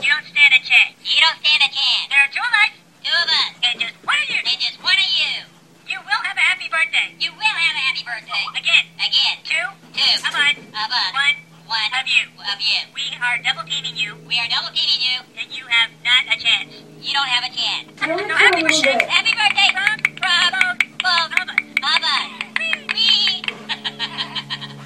You Will Have A Happy Birthday! is a hoops&yoyo greeting card with sound made for birthdays.
Card sound